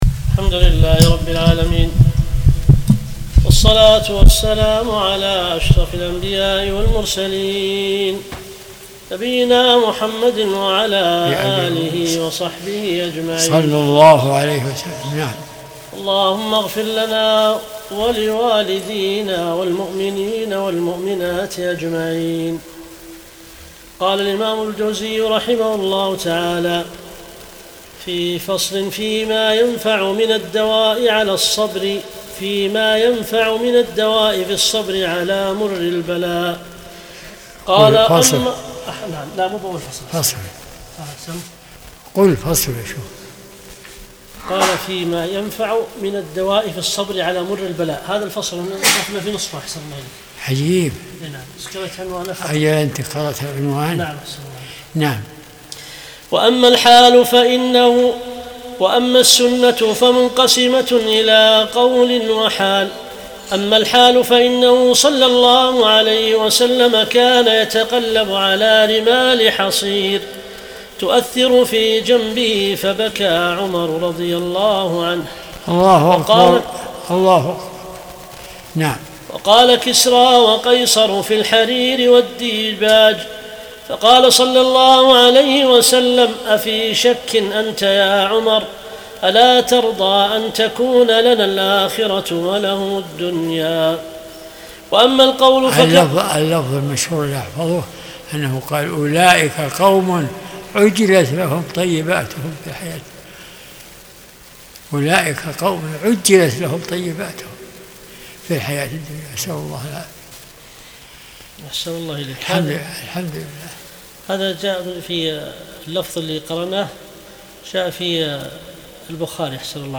درس الأحد 48